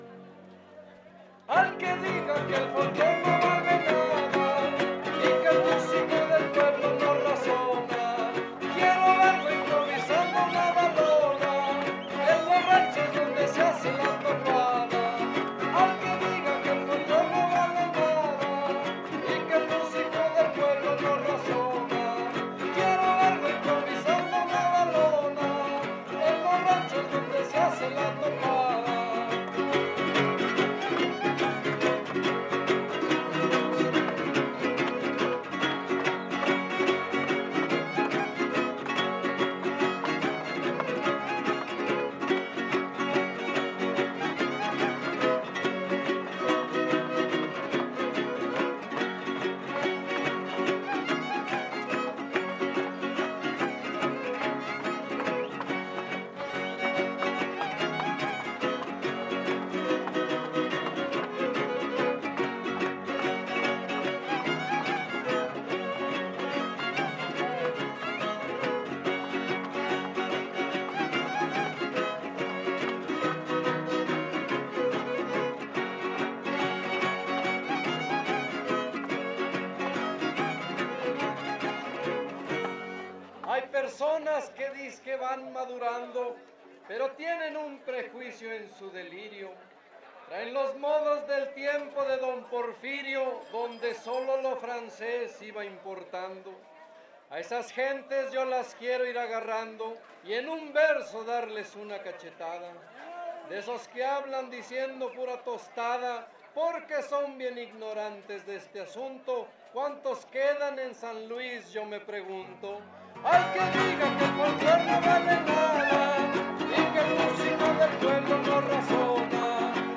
Huapango arribeño Décima
guitarra huapanguera
Violín Vihuela Guitarra
Topada ejidal: Cárdenas, San Luis Potosí